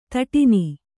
♪ taṭini